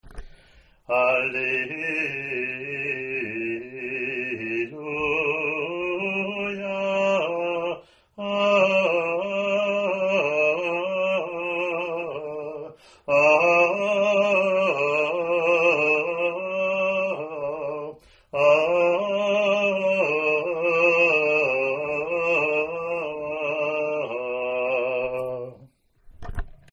Responsorial or Alleluia:  (cantor sings once, schola repeats, cantor sings verses, schola leads assembly in response between verses).
first Alleluia (after first reading)